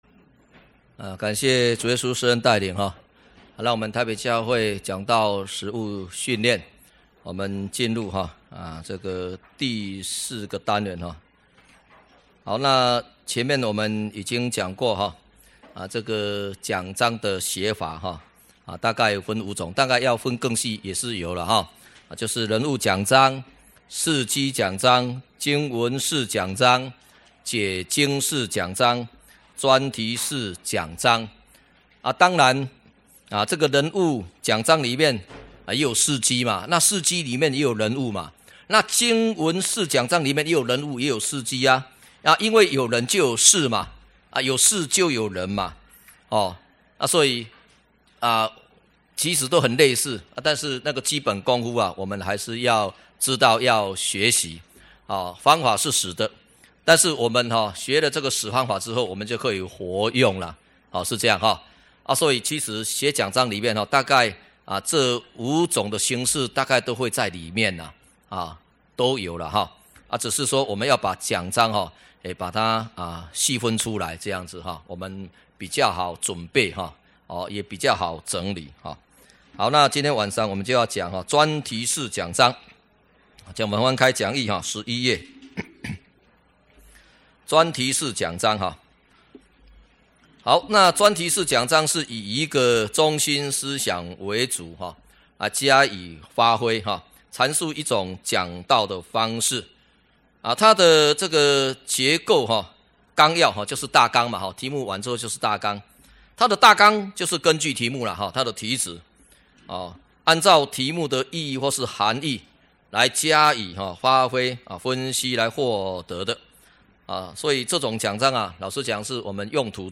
講道實務訓練(四)-講道錄音